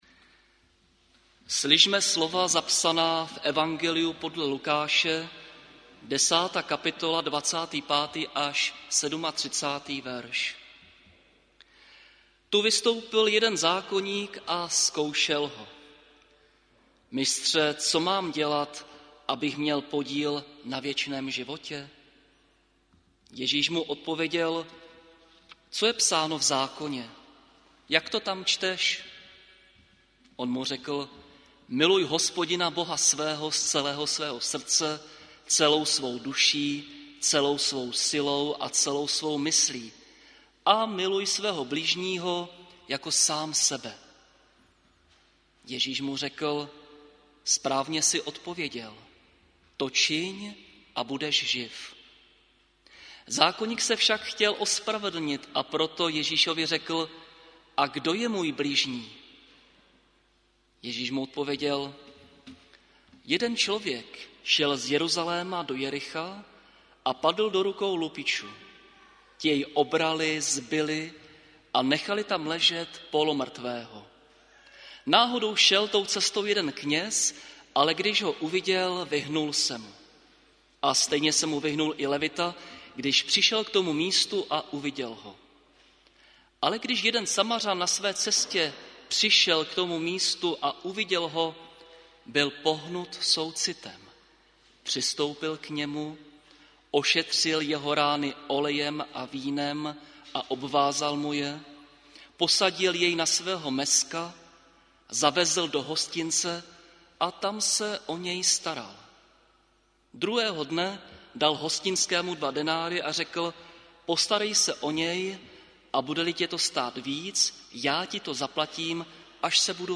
Záznam kázání a sborová ohlášení.